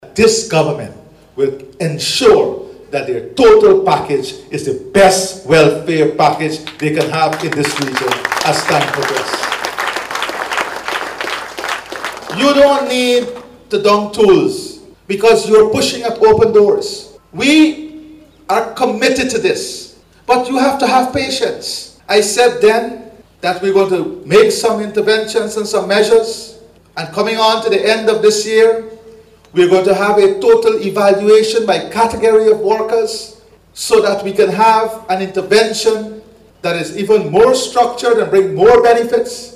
Speaking at the opening of the Guyana Technical Training College Facility Simulator in Port Mourant, President Ali assured all categories of workers that the government is dedicated to incrementally improving their working conditions.